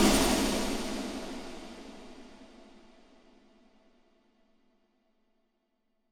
Index of /musicradar/layering-samples/Drum_Bits/Verb_Tails